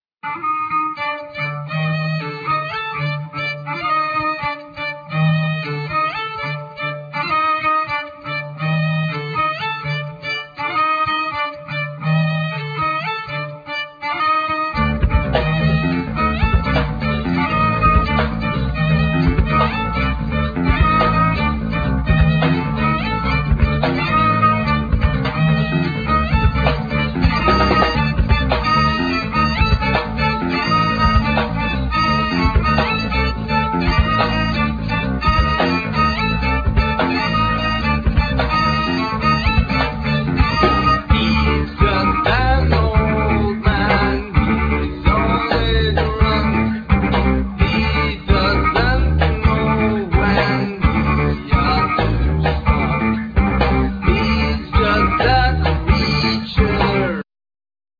Vocal,Violin,Guitar
Cello
Guitar,Bass guitar,Tambourine
Drum,Bass guitar